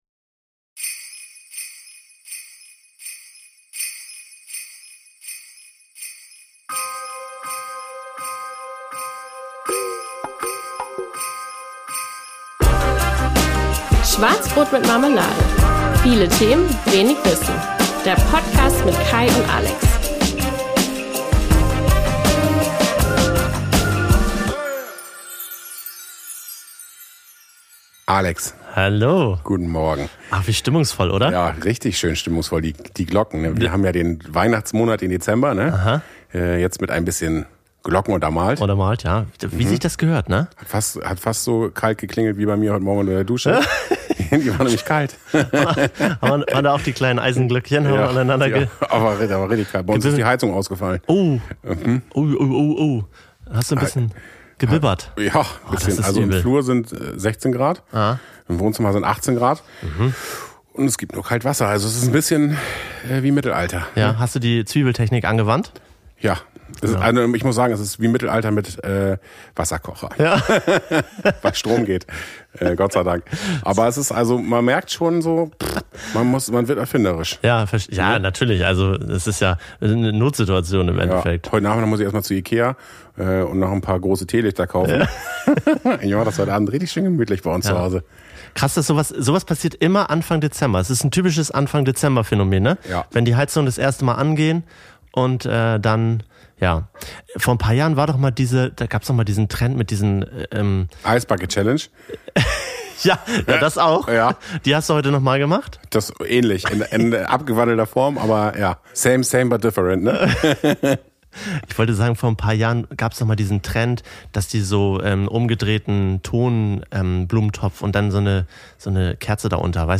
Beschreibung vor 1 Jahr ADVENT, ADVENT DIE CAPSLOCK-TASTE KLEMMT!!!1 Ha, mit diesem 45er starten wir in unsere erste Weihnachtsfolge und die erkennt man nicht nur an den Glocken im Intro.